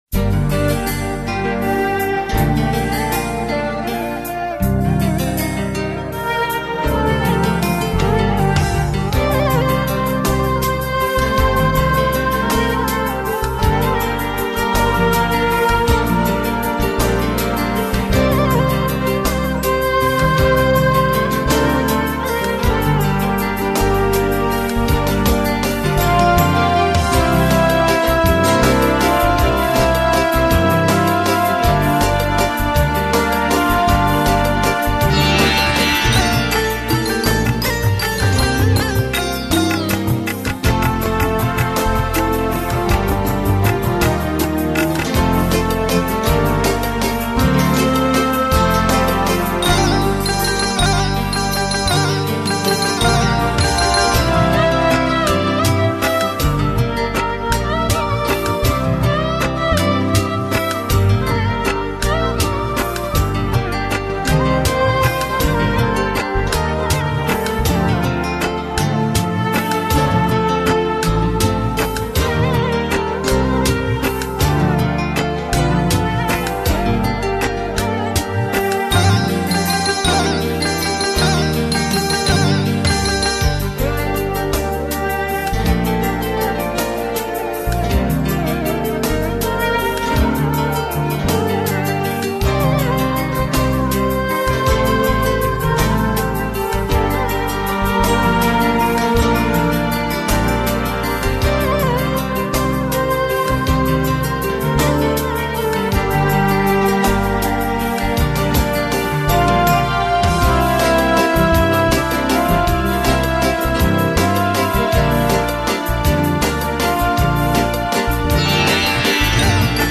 Sinhala Instrumental Mp3 Download